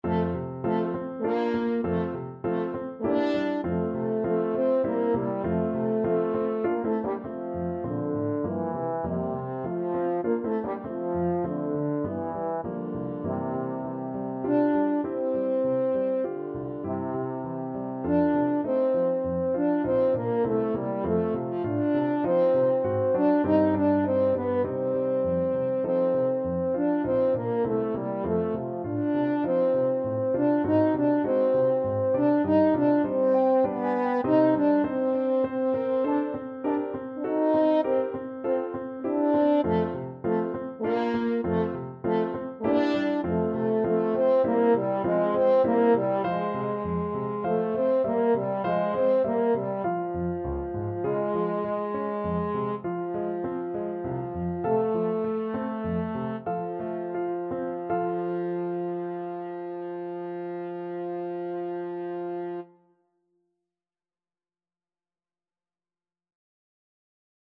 French Horn
3/4 (View more 3/4 Music)
F major (Sounding Pitch) C major (French Horn in F) (View more F major Music for French Horn )
~ = 100 Allegretto grazioso (quasi Andantino) (View more music marked Andantino)
Classical (View more Classical French Horn Music)